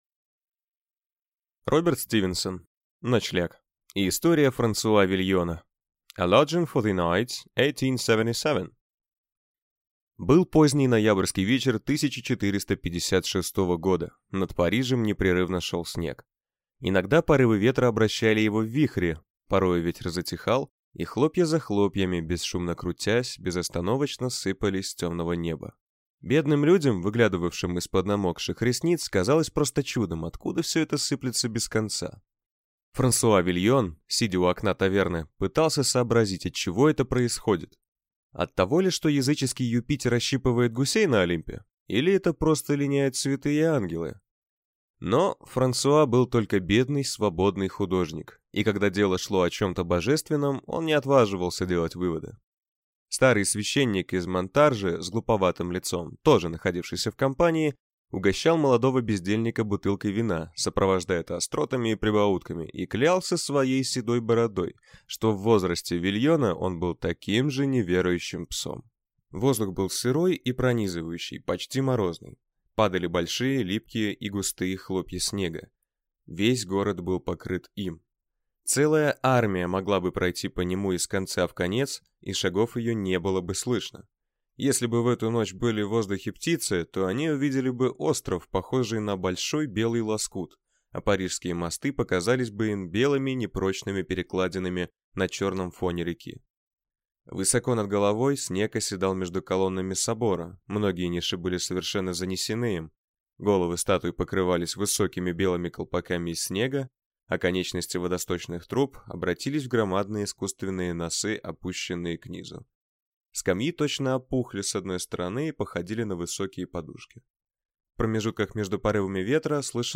Аудиокнига Ночлег | Библиотека аудиокниг
Прослушать и бесплатно скачать фрагмент аудиокниги